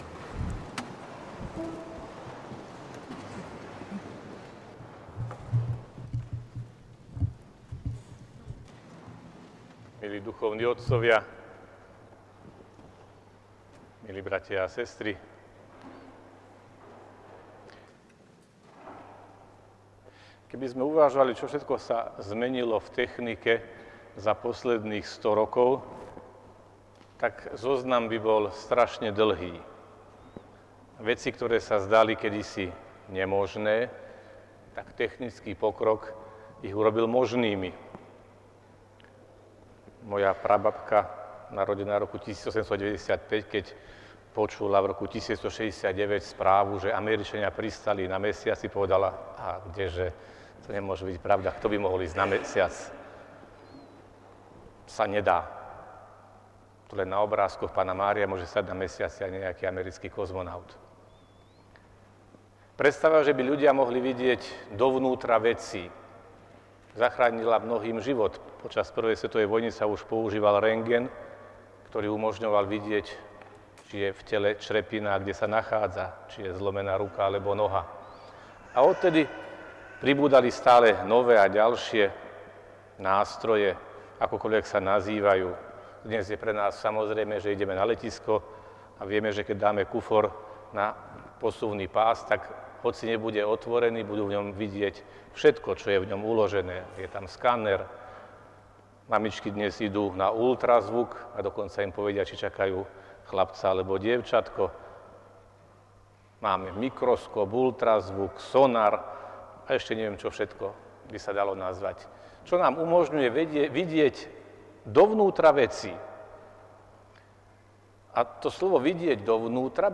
Celý program bol vysielaný v priamom prenose TV Logos a TV Zemplín.
V homílii sa vladyka Cyril Vasiľ zameral na otázku pôsobenia Svätého Ducha a ako sa prejavuje vo sviatostiach.